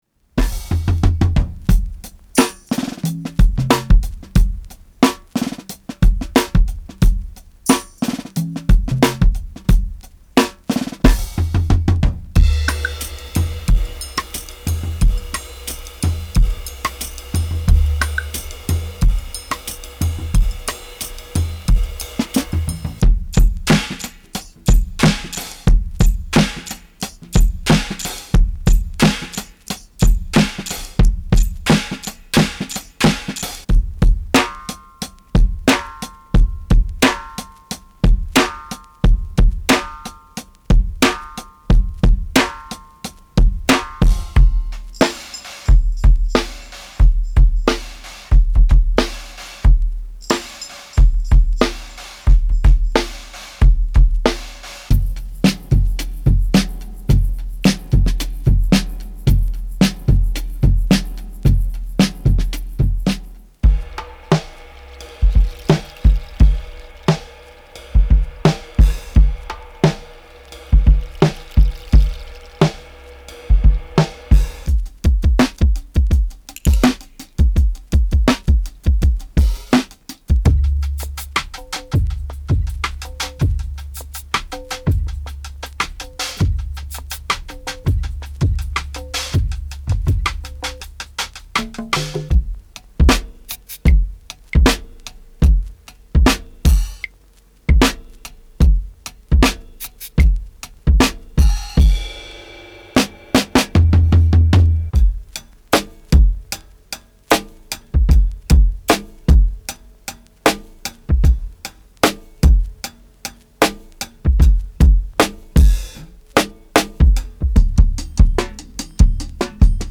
Genre:Hip Hop
少し遊び心を加え、パーカッションを随所に取り入れることで、トラックに風味豊かなアクセントを加えています。
デモサウンドはコチラ↓
42 Drum break loops
28 Percussion loops
65 - 110 bpm